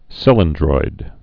(sĭlən-droid)